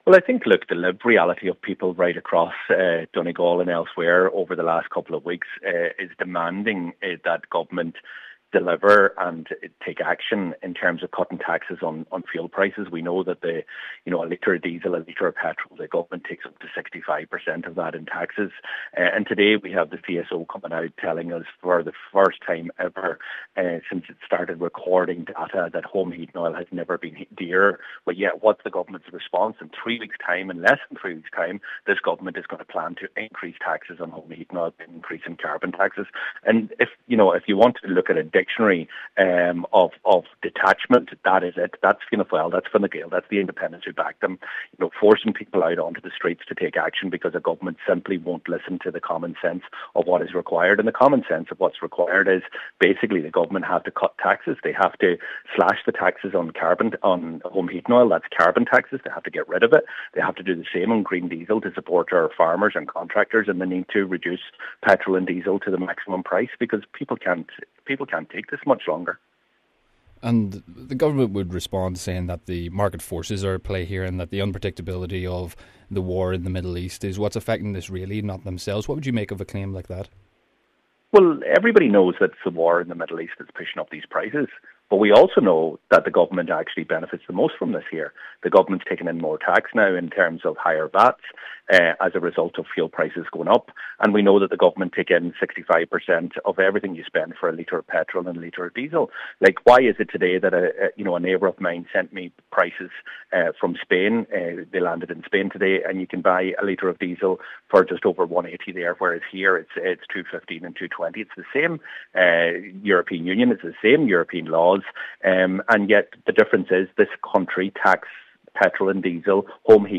Party finance spokesperson Pearse Doherty says the Government’s recent actions is a dictionary definition of detachment: